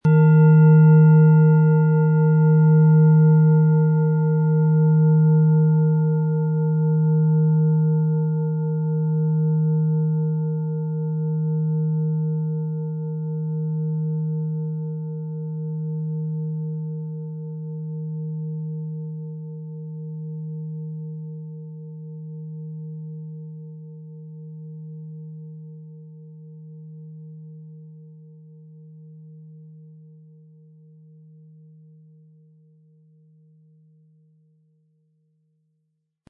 • Mittlerer Ton: Mond
Sie möchten den schönen Klang dieser Schale hören? Spielen Sie bitte den Originalklang im Sound-Player - Jetzt reinhören ab.
Mit einem sanften Anspiel "zaubern" Sie aus der Eros mit dem beigelegten Klöppel harmonische Töne.
PlanetentöneEros & Mond
MaterialBronze